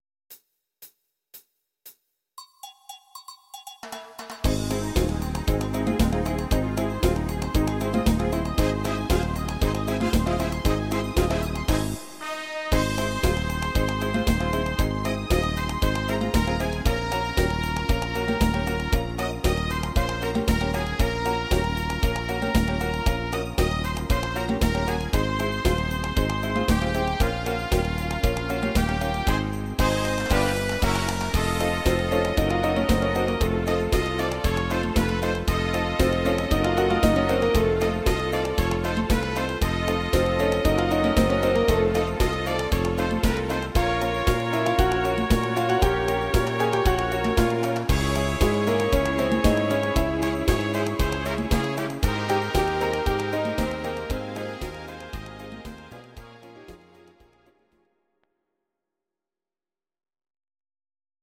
instr. Orgel